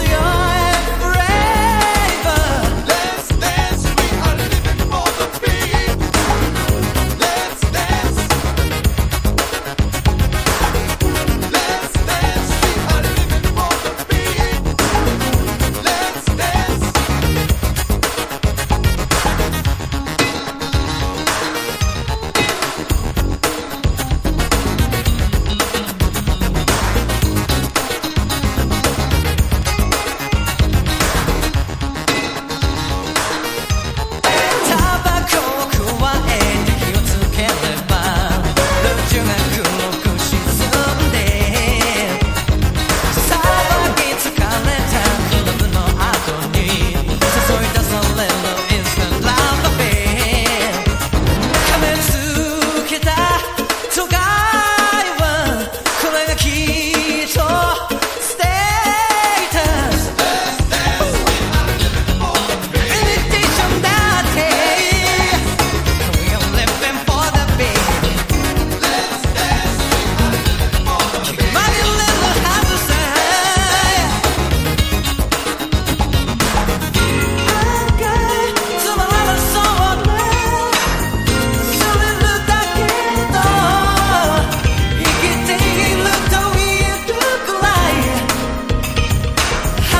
• CLUB